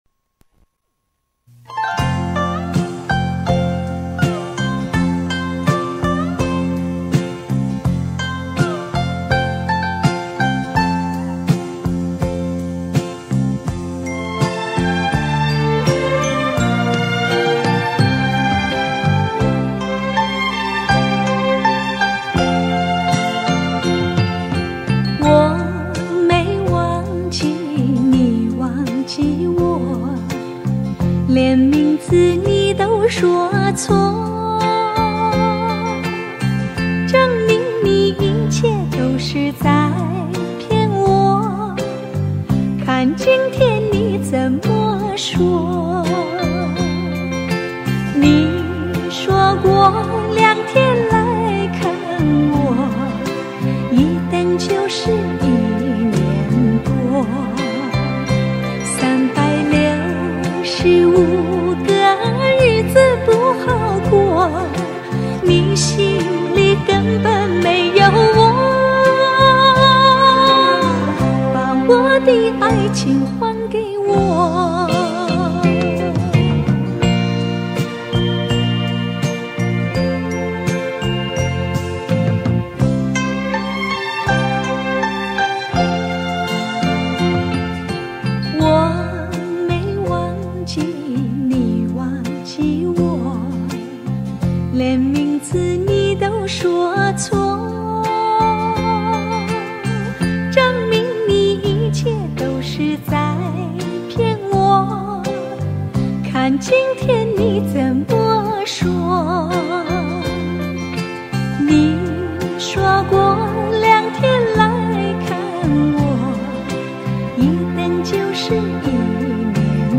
Popular Chinese Song
Solo Recorder